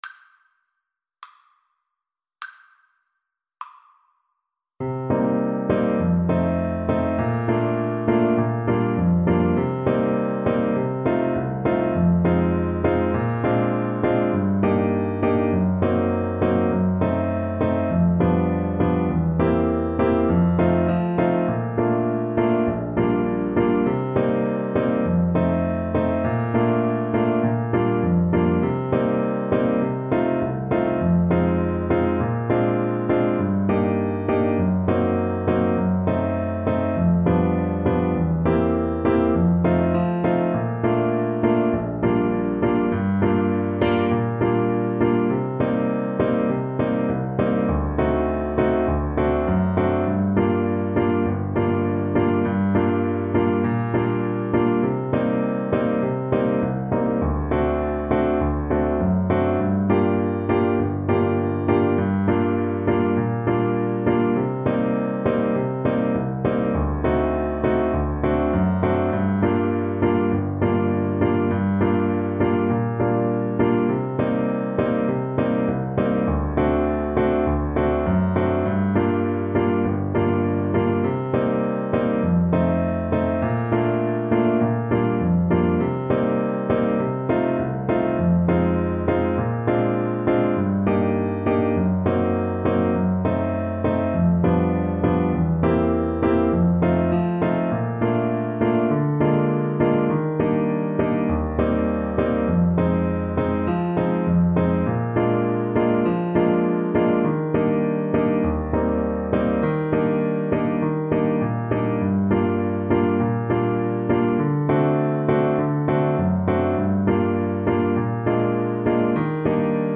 Play (or use space bar on your keyboard) Pause Music Playalong - Piano Accompaniment Playalong Band Accompaniment not yet available transpose reset tempo print settings full screen
Trumpet
Bb major (Sounding Pitch) C major (Trumpet in Bb) (View more Bb major Music for Trumpet )
2/4 (View more 2/4 Music)
( = c.69)
World (View more World Trumpet Music)